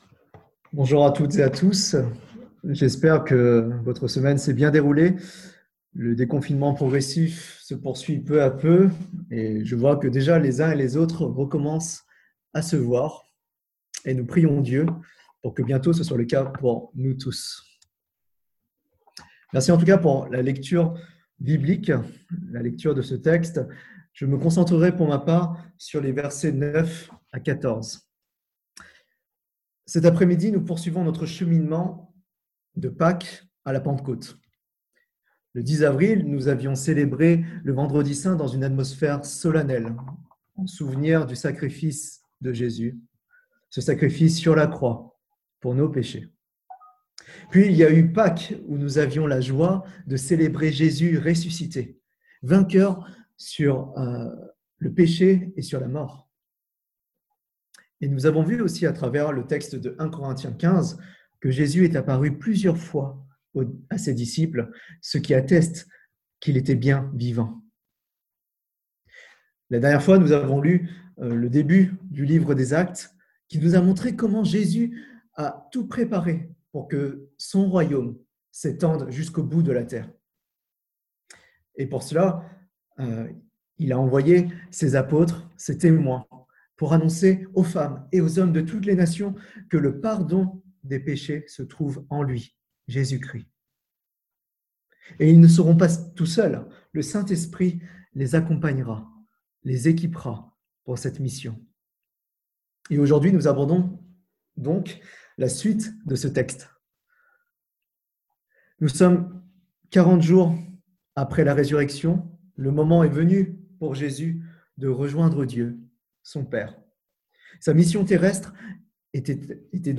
Plan de la prédication L’absence de Jésus n’est pas la fin de l’histoire L’absence de Jésus est temporaire L’absence de Jésus ne met pas fin aux réunions de prière 1.